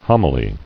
[hom·i·ly]